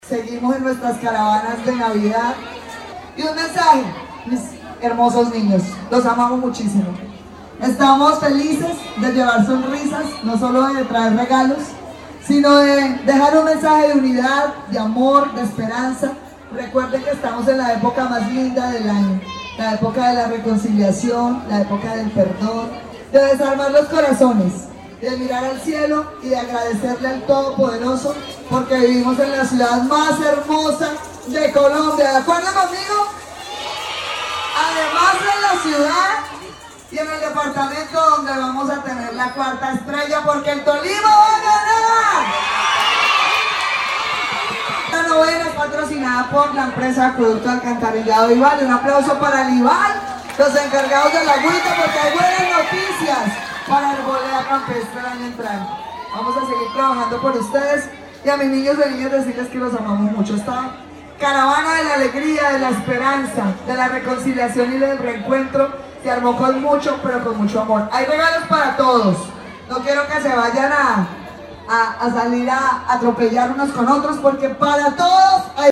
En un ambiente lleno de música, alegría y espíritu navideño, la alcaldesa Johana Aranda llevó a cabo la cuarta novena de Navidad en Arboleda del Campestre, comuna 9, en compañía del IBAL e Infibagué.
Johana-Aranda-alcaldesa-de-Ibague-.mp3